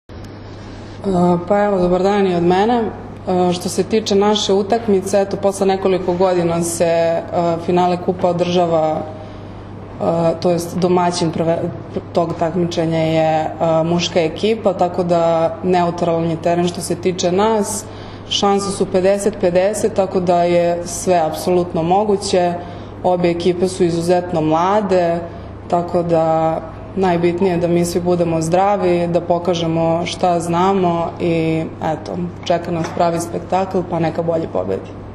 U beogradskom hotelu “M” danas je održana konferencija za novinare povodom finalnih utakmica 59. Kupa Srbije 2024/2025. u konkurenciji odbojkašica i jubilarnog, 60. Kupa Srbije 2024/2025. u konkurenciji odbojkaša, koje će se odigrati se u “MTS hali Jezero” u Kragujevcu u nedelju, 23. februara.
Izjava